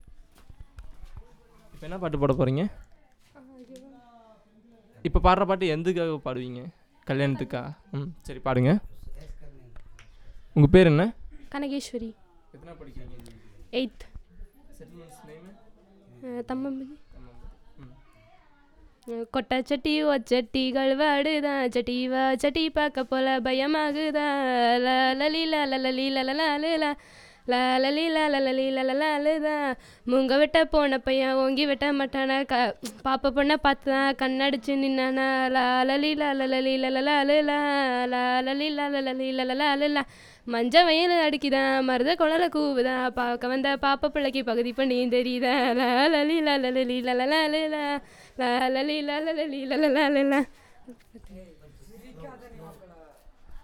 Performance of traditional song
NotesThis is a performance of traditonal marriage song for the purpose of recording. The song is about a boy who goes to forest to cut bamboos and a girl.